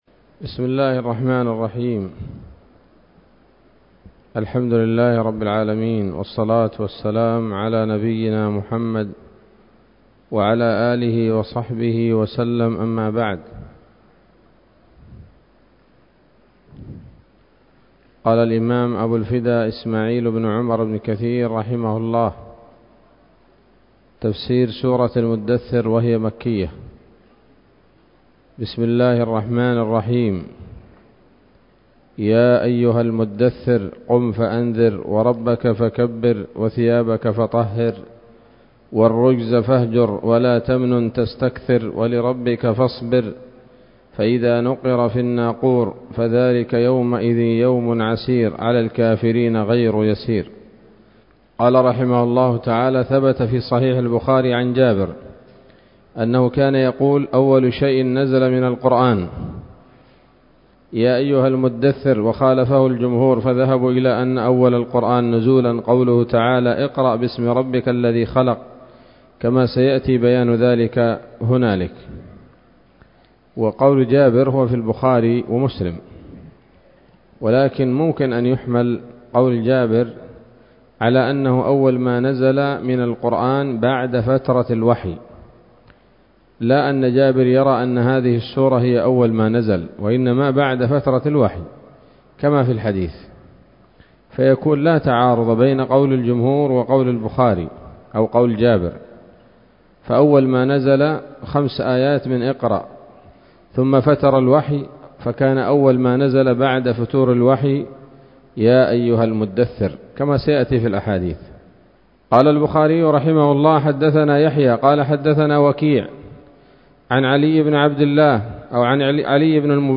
الدرس الأول من سورة المدثر من تفسير ابن كثير رحمه الله تعالى